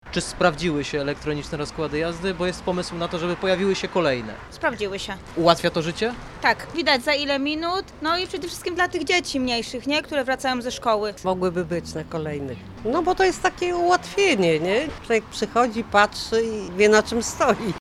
Mieszkańcy uważają, że to spore ułatwienie: